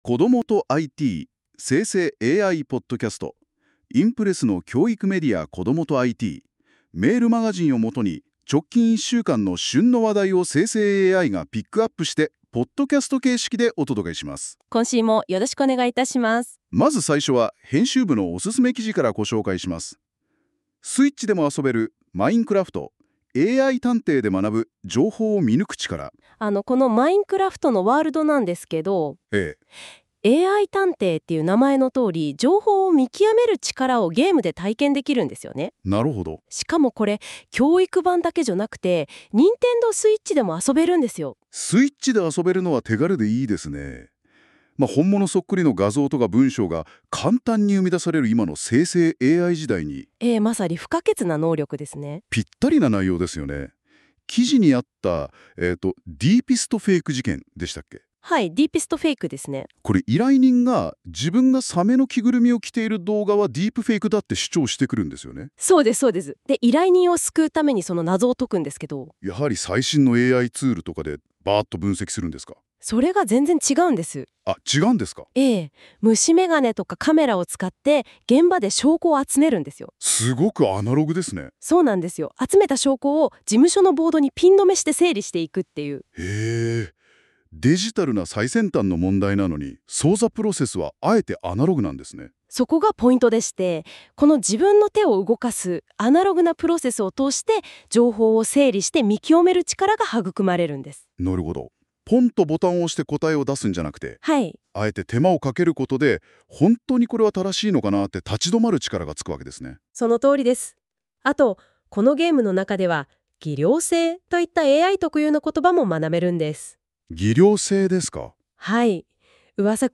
この記事は、『こどもとIT』メールマガジンを元に、先週１週間の旬の話題をNotebookLMでポッドキャストにしてお届けする、期間限定の実験企画です。 ※生成AIによる読み上げは、不自然なイントネーションや読みの誤りが発生します。 ※この音声は生成AIによって記事内容をもとに作成されています。